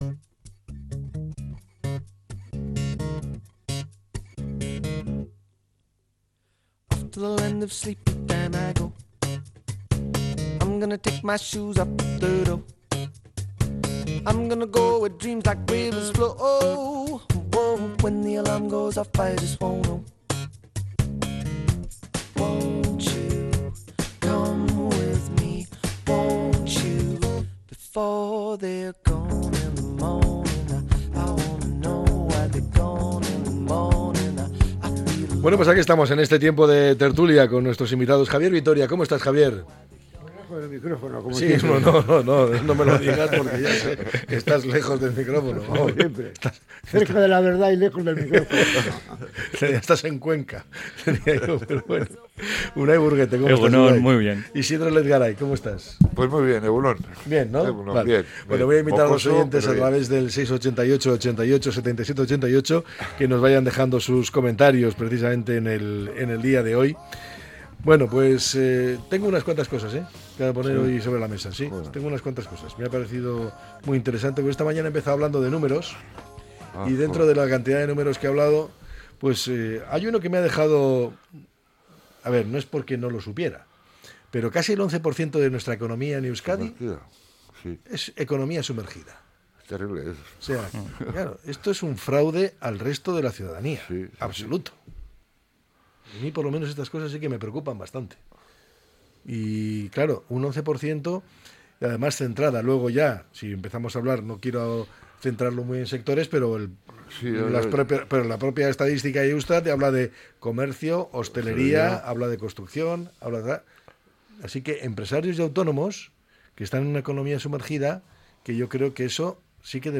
La tertulia 01-04-25.